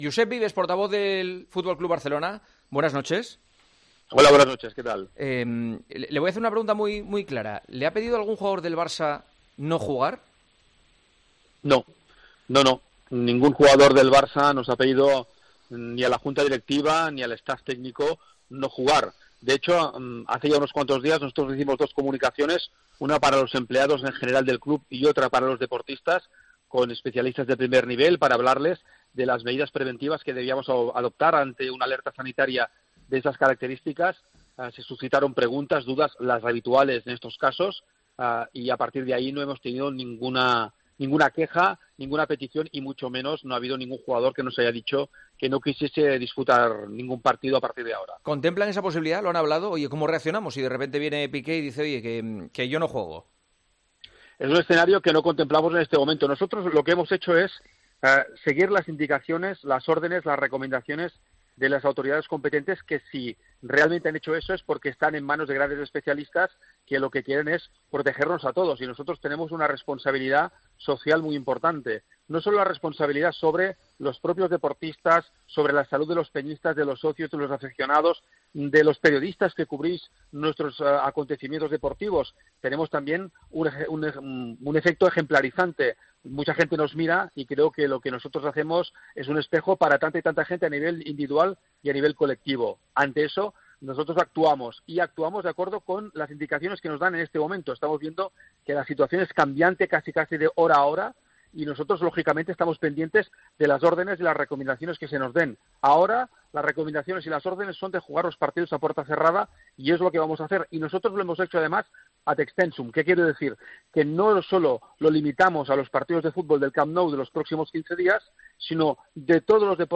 la entrevista concedida a Juanma Castaño